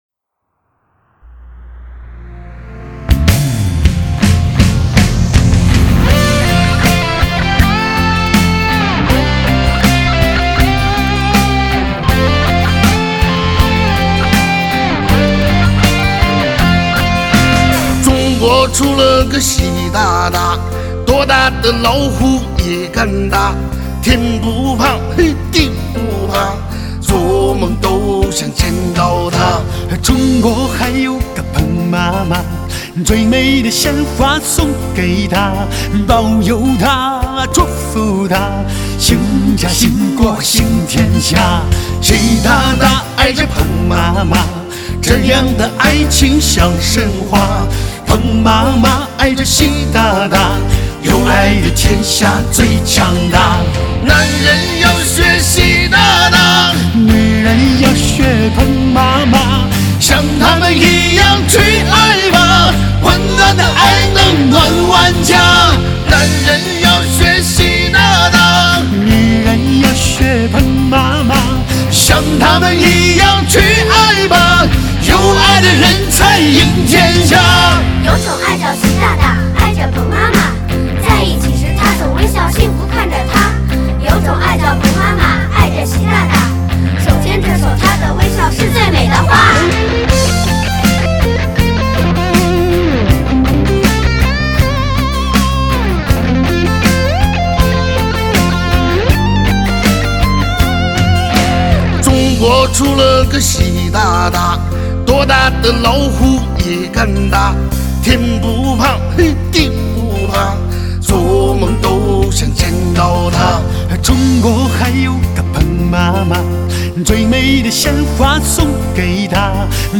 年度最值收藏曲目，人声器乐唯美契合
音效极致HIFI的最潮流行热盘，精选华语流行乐坛各大音乐排行榜最畅销热曲